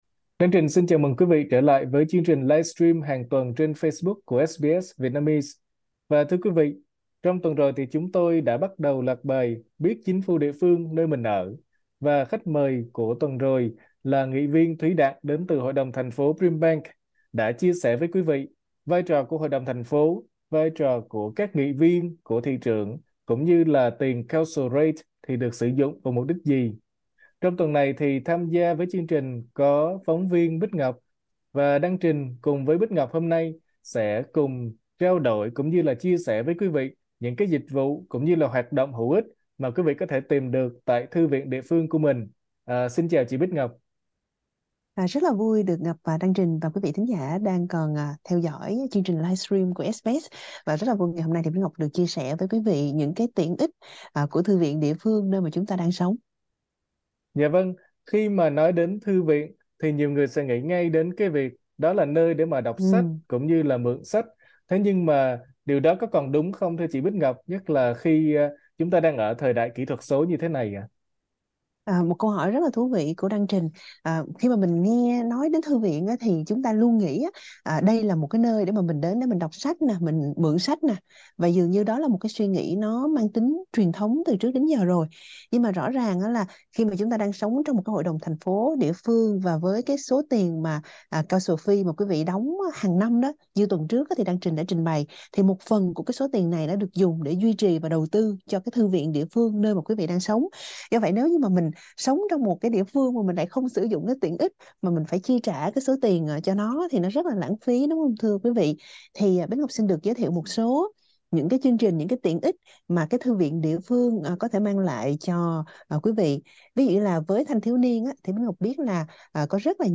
Mời nhấn vào phần audio để nghe toàn bộ bài phỏng vấn.